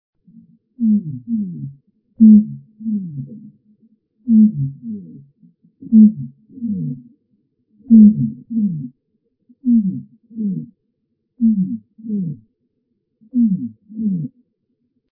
TETRIX TENUICORNIS TENUICORNIS - ������������ ������� ��������� ����� �� �����������